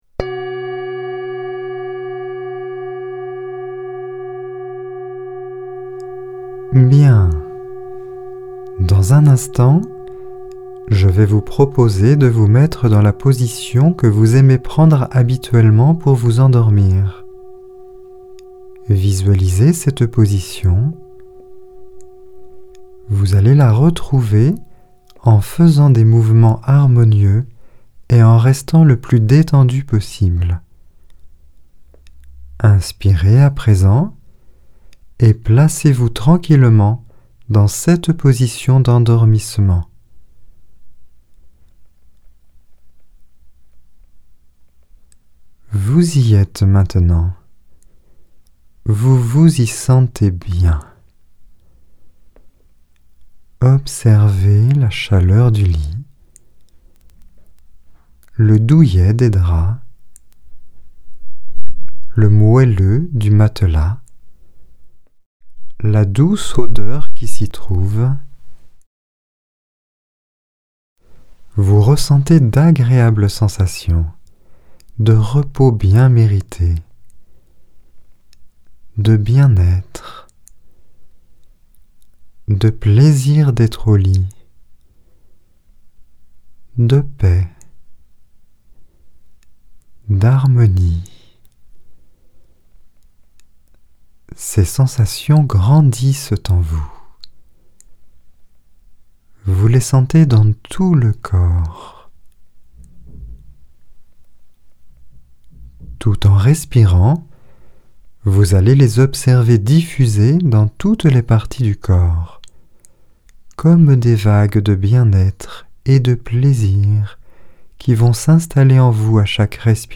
Genre : Meditative.
04-Relaxations-facilitant-lendormissement-sensations-agreables.mp3